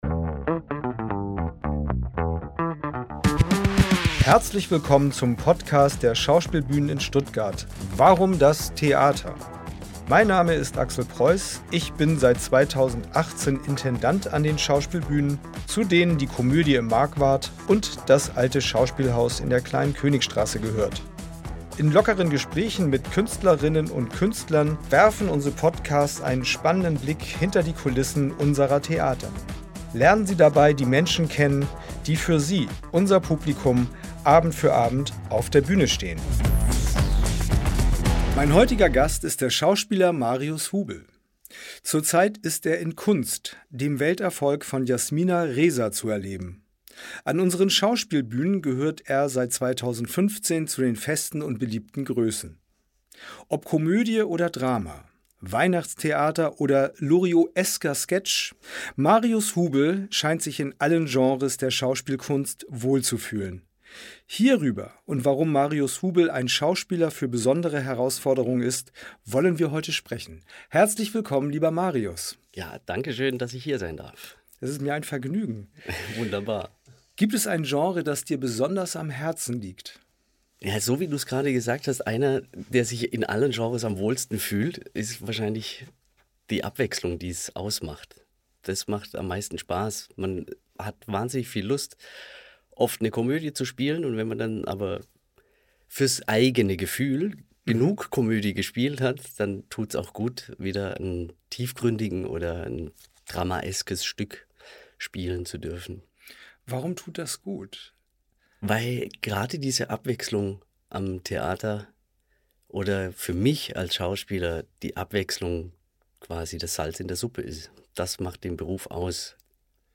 Unser Schauspielbühnen-Talk präsentiert Ihnen regelmäßig Gespräche mit unseren Künstlerinnen und Künstlern. Lernen Sie die Menschen hinter den Bühnencharakteren kennen und erfahren Sie, wie Theater hinter den Kulissen funktioniert.